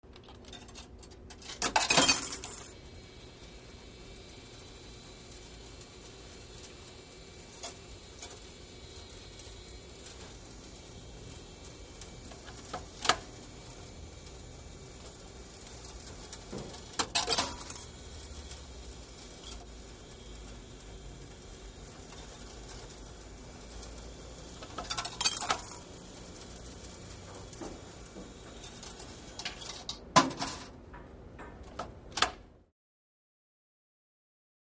Coins Into Cigarette Vending Machine, W Cu Mechanical Activity.